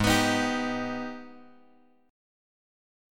G# Minor Major 7th
G#mM7 chord {4 x x 4 4 3} chord
Gsharp-Minor Major 7th-Gsharp-4,x,x,4,4,3.m4a